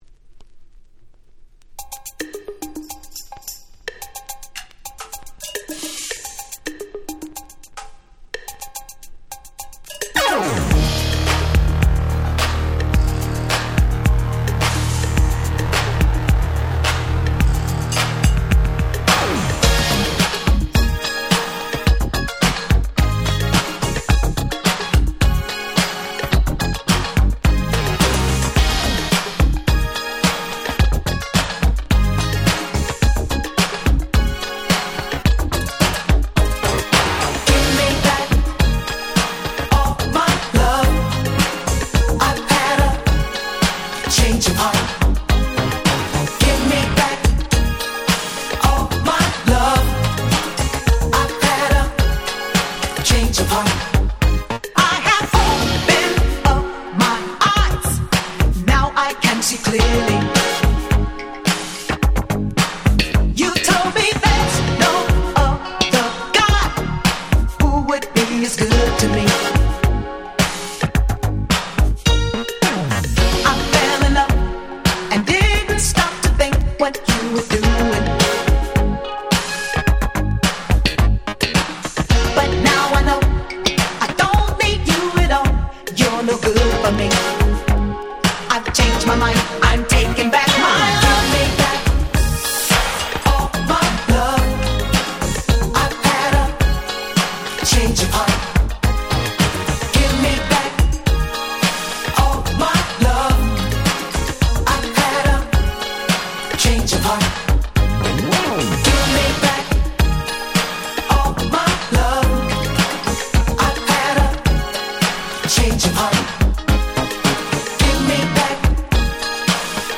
84' Smash Hit Disco / Boogie !!